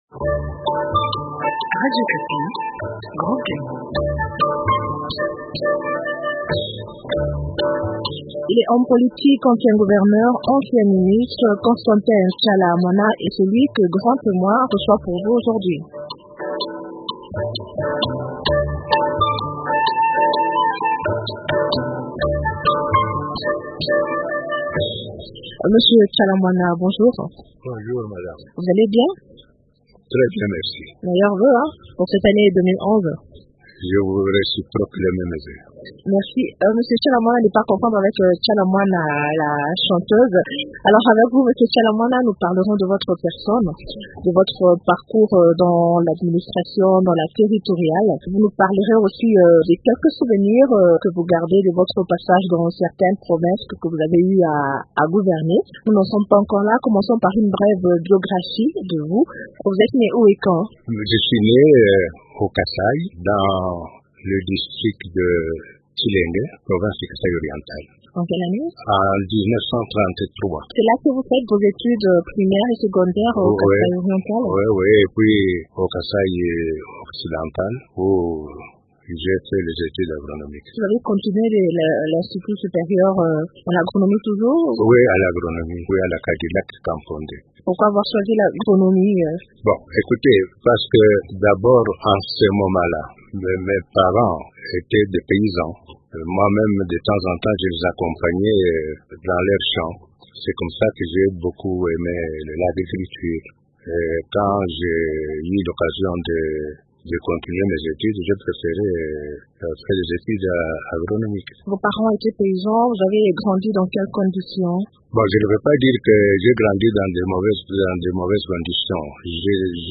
Constantin Tshiala Mwana raconte son expérience à la tête du Maniema
Constantin Tshiala Mwana, le tout premier gouverneur du Maniema.